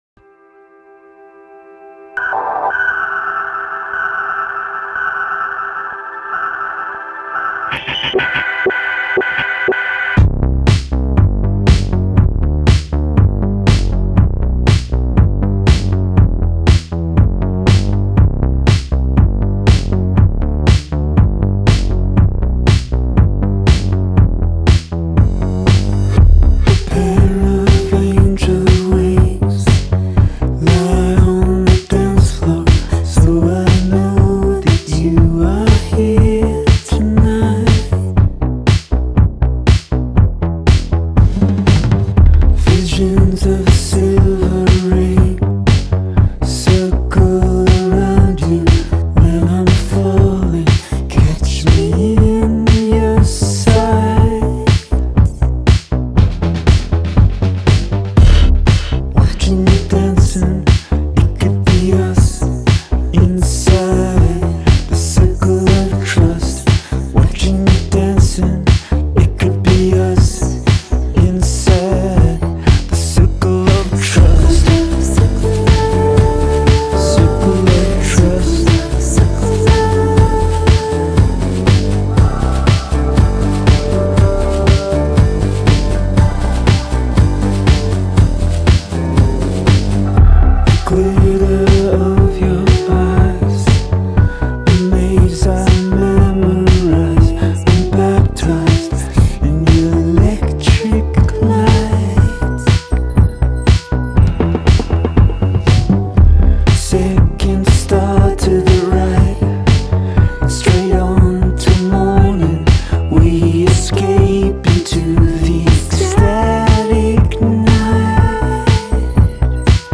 Big without being bombastic.